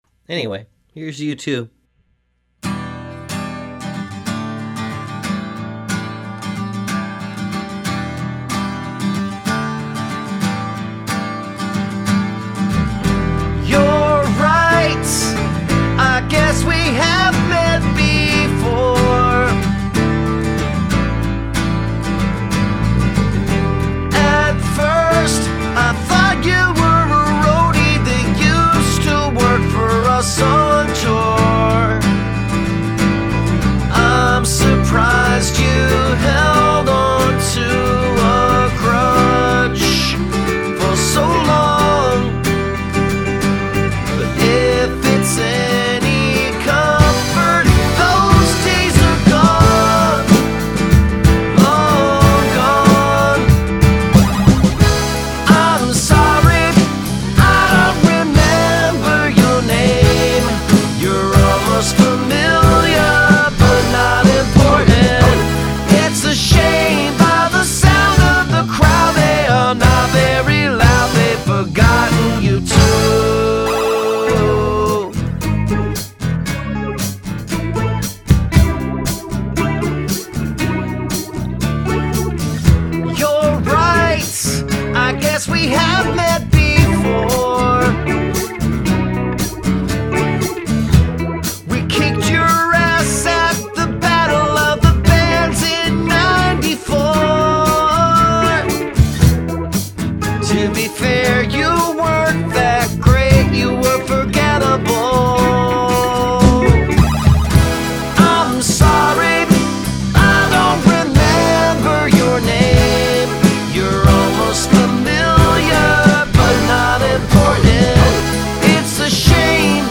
Contrafact chorus or contrafact verse(s)
Vocal samples: